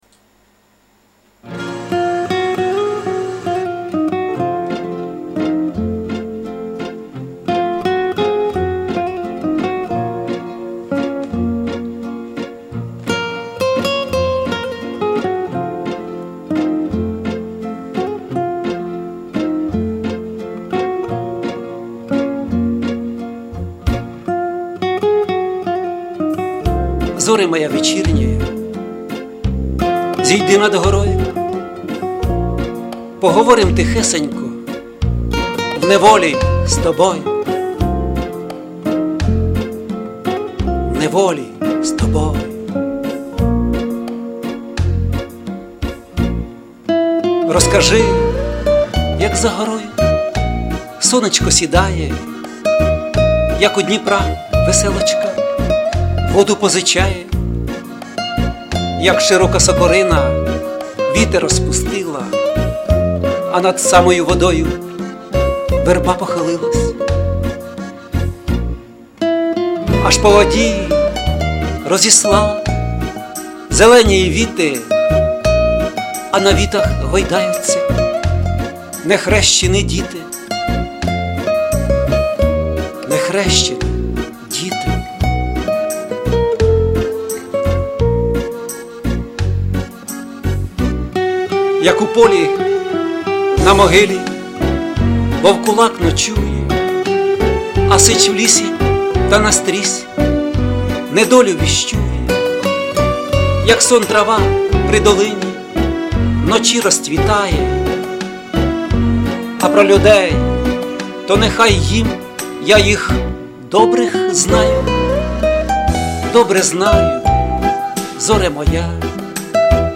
Концерт пам'яті Т.Г.Шевченка і до 200-річчя